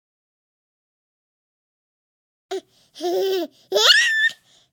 tickle7.ogg